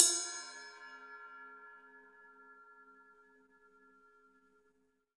Index of /90_sSampleCDs/USB Soundscan vol.10 - Drums Acoustic [AKAI] 1CD/Partition C/03-GATEKIT 3